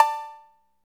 Index of /90_sSampleCDs/Roland - Rhythm Section/DRM_Drum Machine/KIT_TR-808 Kit